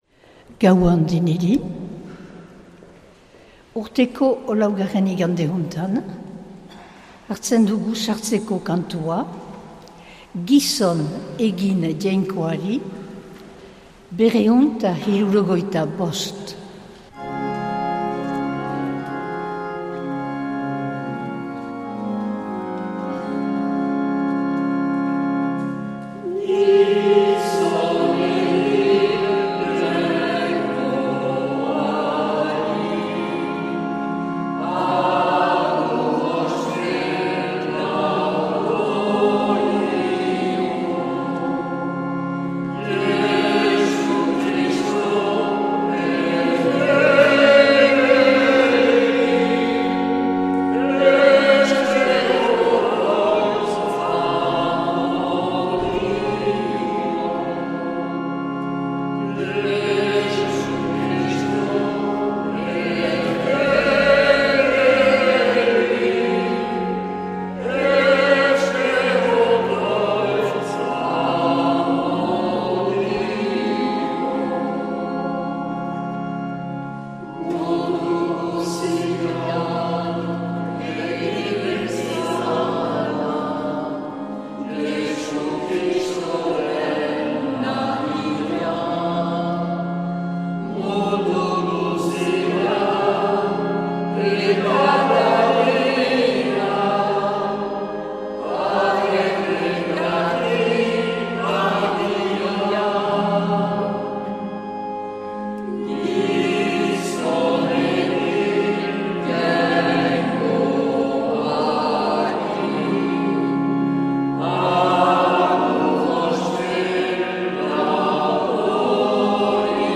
2026-02-01 Urteko 4. Igandea A - Hazparne
Accueil \ Emissions \ Vie de l’Eglise \ Célébrer \ Igandetako Mezak Euskal irratietan \ 2026-02-01 Urteko 4.